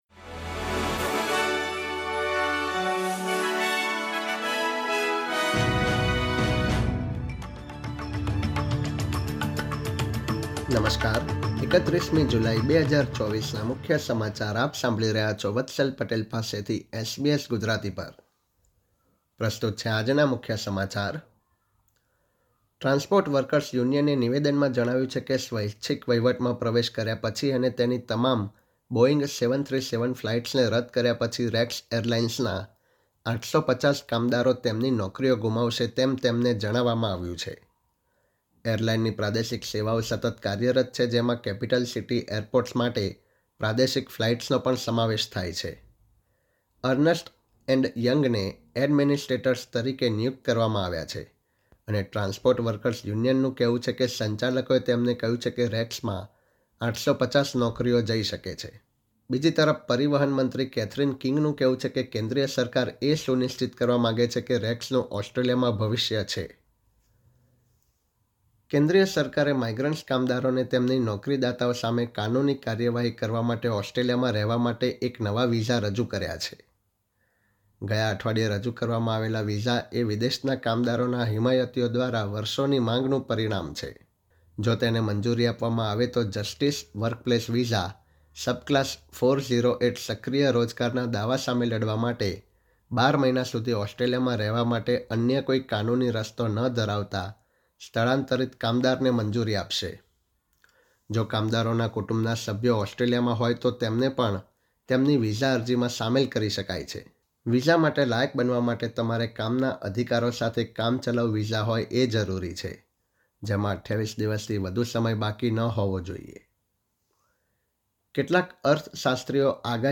SBS Gujarati News Bulletin 31 July 2024